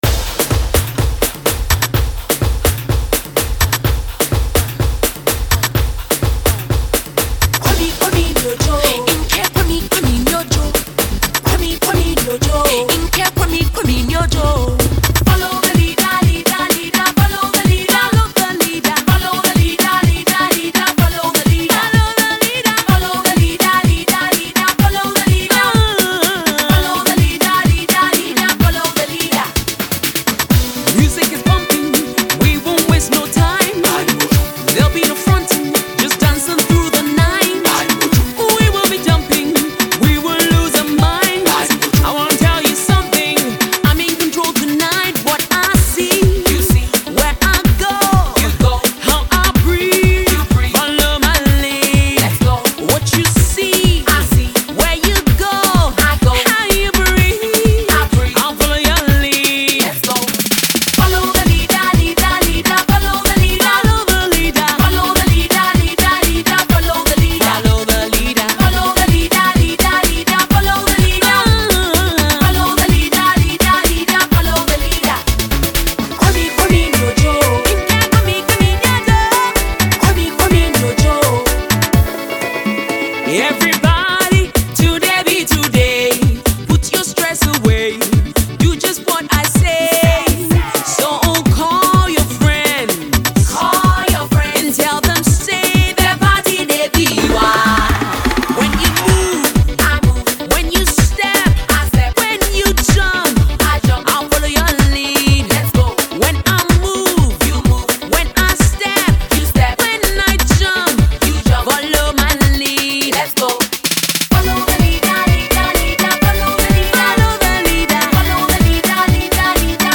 returns with an uptempo Afro Pop song